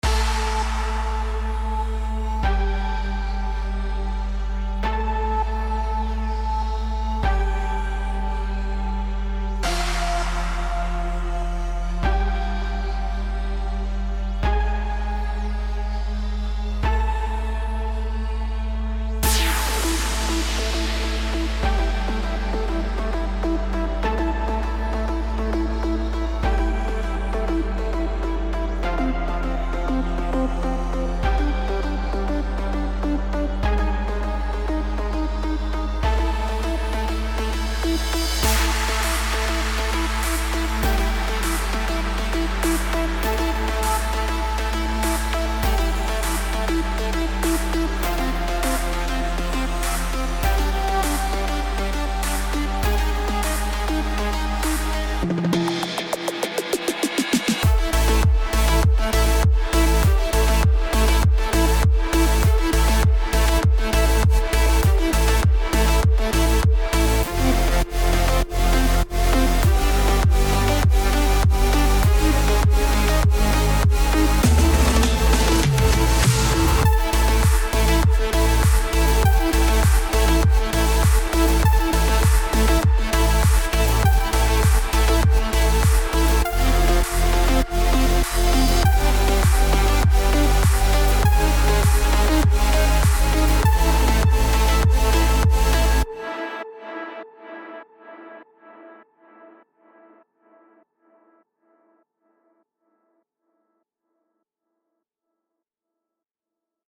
האוס.mp3 מוזיקה האוס { נראה לי שככה קוראים לזאנר}, תחקו לשנייה ה 55